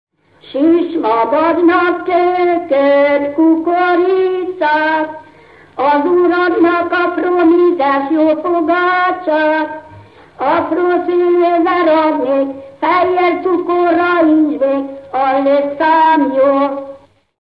ének
Magyar Rádió Stúdiója